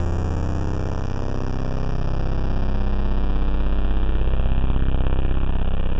Index of /90_sSampleCDs/Trance_Explosion_Vol1/Instrument Multi-samples/Angry Trance Pad
C1_angry_trance_pad.wav